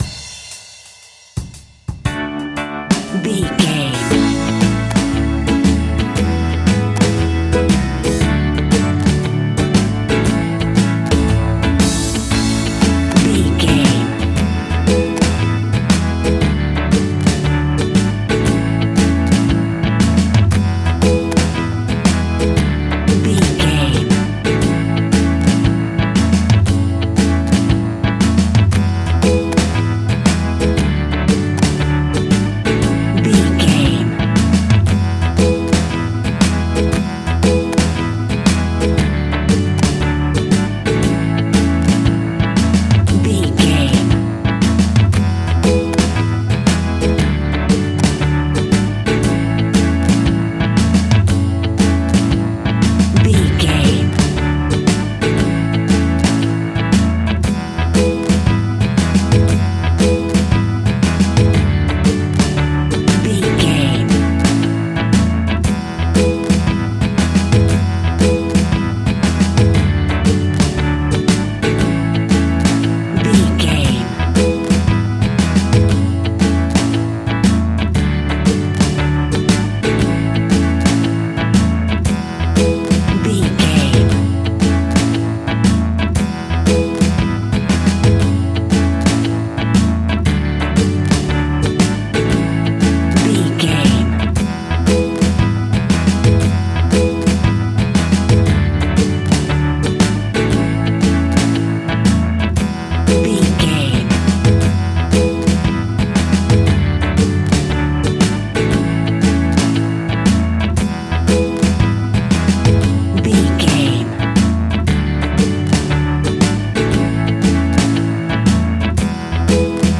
Folk Country Music Theme.
Ionian/Major
happy
upbeat
bright
bouncy
drums
bass guitar
electric guitar
hammond organ
acoustic guitar
percussion